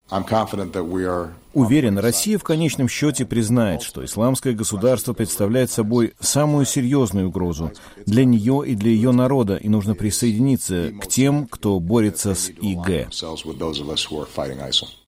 Выступая в кулуарах международного саммита по климату, проходящего под Парижем, Обама отметил, что не ожидает скорого изменения стратегии президента России Владимира Путина в отношении Сирии, но надеется, что, в конце концов, Москва может присоединиться к коалиции во главе с США, которая проводит операцию против ИГ.
Говорит президент США Барак Обама (Сирия и Россия)